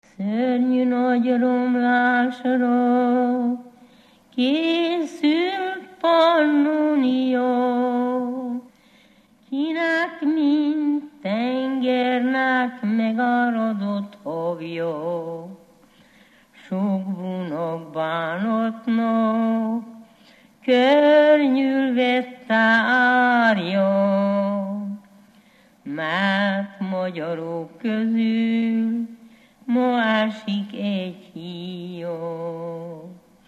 Alföld - Torontál vm. - Hertelendyfalva
Stílus: 4. Sirató stílusú dallamok
Kadencia: 5 (1) b3 1